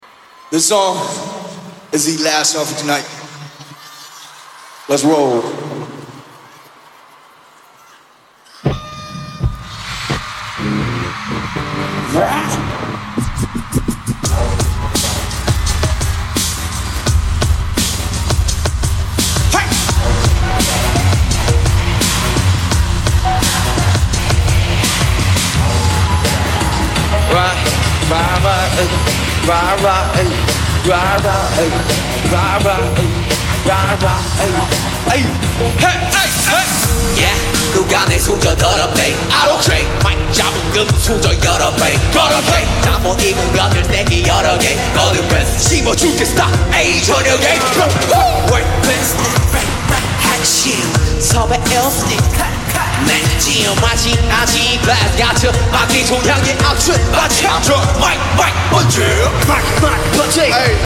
(got muted, cut it short)